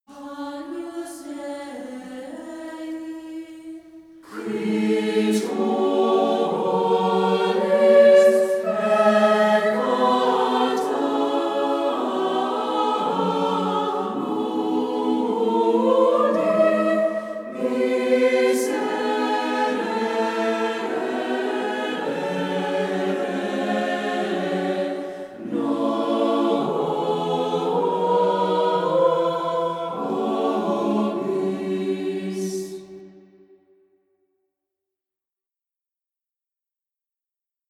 * Mp3 Download • MEDIAEVAL AGNUS DEI